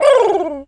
pgs/Assets/Audio/Animal_Impersonations/pigeon_call_angry_16.wav at master
pigeon_call_angry_16.wav